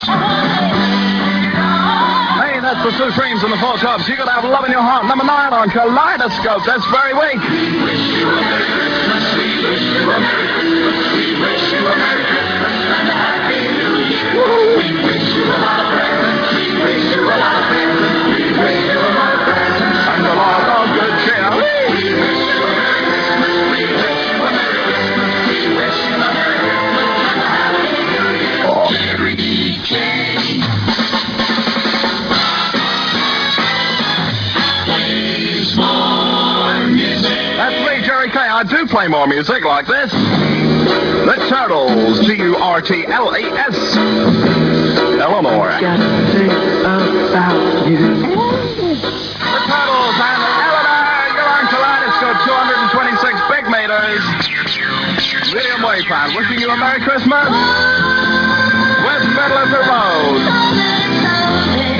The airchecks feature the station, in its heyday, signing on at 1000 hrs on Sunday December 29th 1973 and two programming extracts from around the same period.